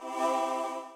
Halo revive sound effect
revive.ogg